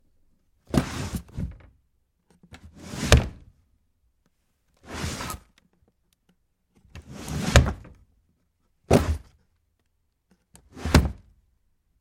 随机的" 抽屉里的木头垃圾 深深的打开关闭响声
描述：抽屉木材的垃圾深开关闭rattle.wav
标签： 打开 关闭 抽屉 拨浪鼓 垃圾
声道立体声